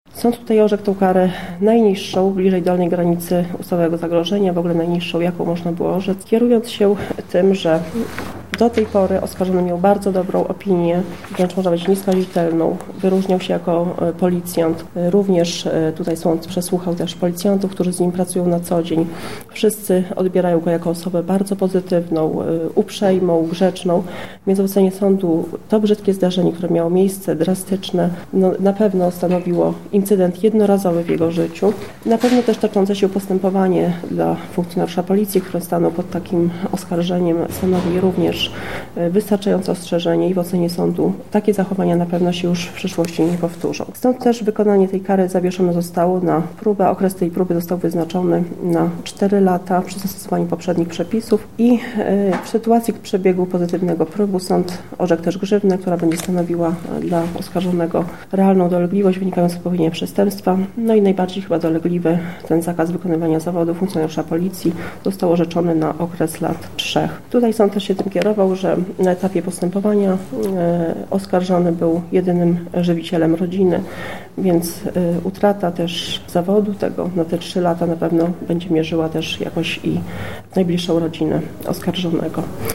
Sędzia Anna Dąbrowska uzasadniła ten wyrok w następujący sposób.